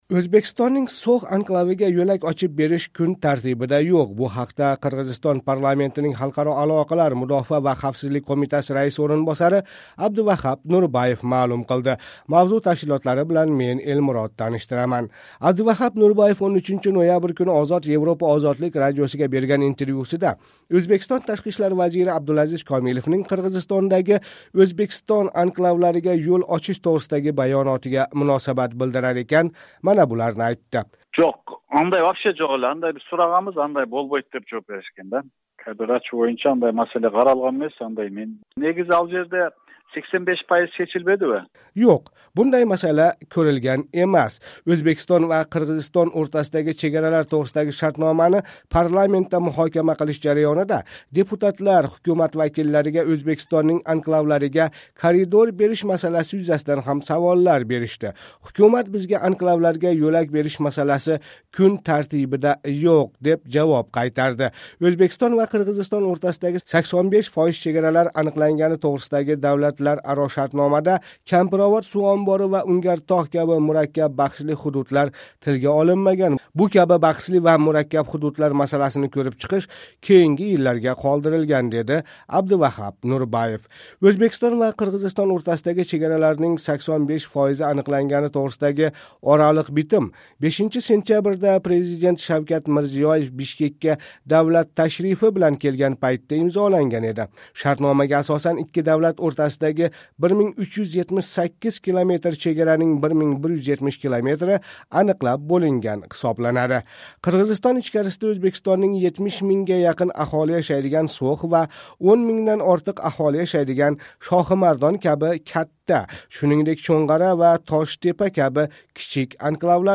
Қирғизистон парламентининг Халқаро алоқалар, мудофаа ва хавфсизлик қўмитаси раиси ўринбосари Абдувахап Нурбаев 13 ноябрь куни Озод Европа –Озодлик радиосига берган интервьюсида Ўзбекистон ташқи ишлар вазири Абдулазиз Комиловнинг Қирғизистондаги Ўзбекистон анклавларига йўл очиш тўғрисидаги баёнотига муносабат билдирар экан, мана буларни айтди: